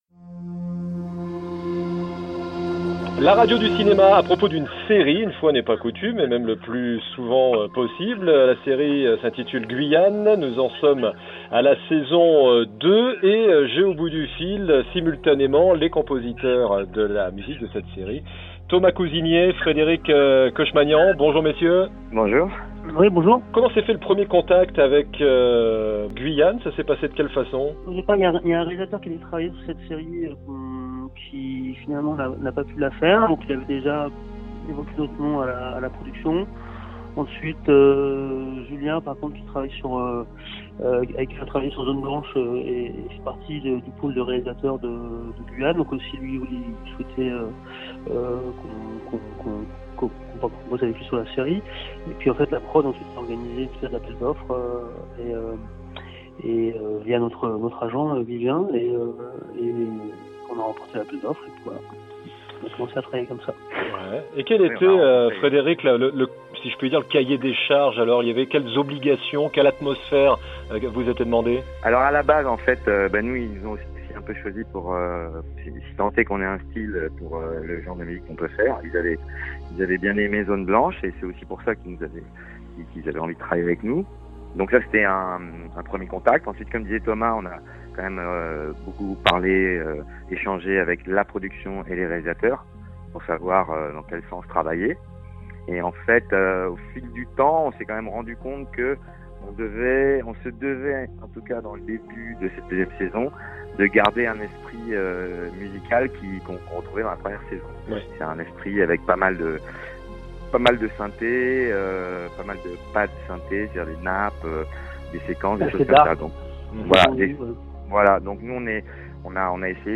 La saison 2 de GUYANE est apparue sur les écrans le 24 septembre 2018 sur Canal+ ; interview à distance des compositeurs, l'un à Paris, l'autre en Ardèche.